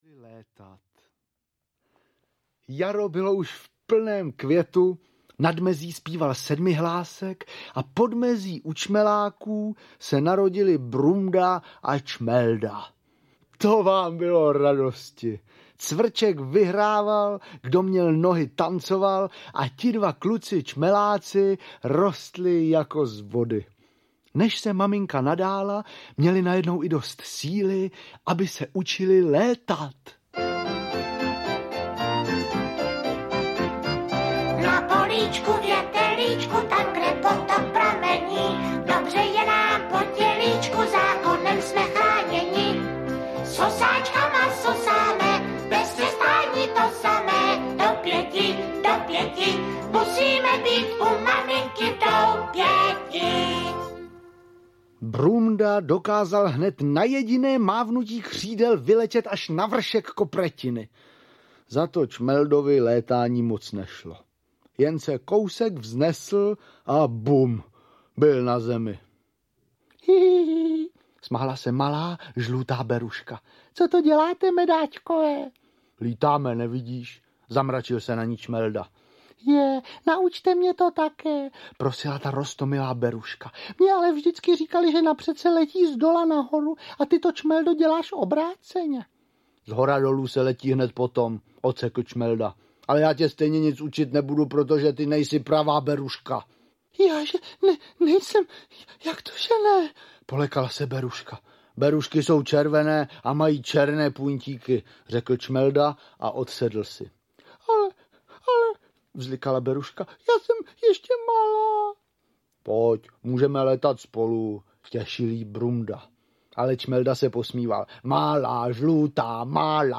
Příběhy včelích medvídků (komplet) audiokniha
Ukázka z knihy